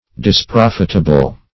Disprofitable \Dis*prof"it*a*ble\, a.